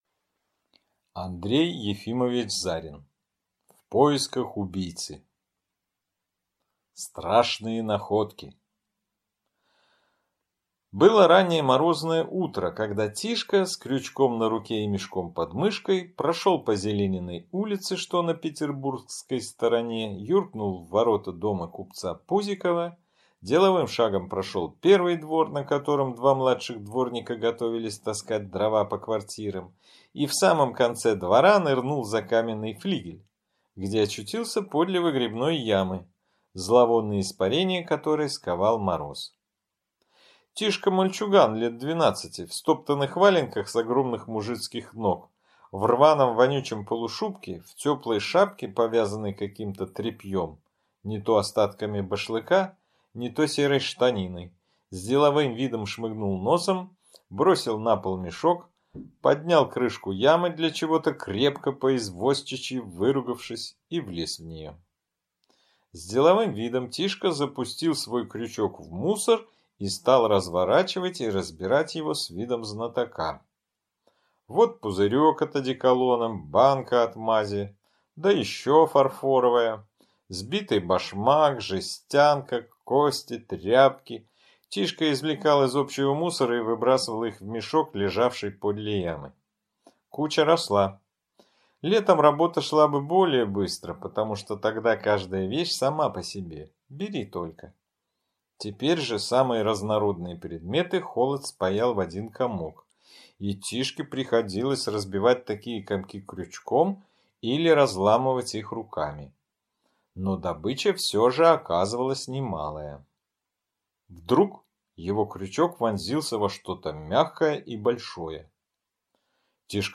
Аудиокнига В поисках убийцы | Библиотека аудиокниг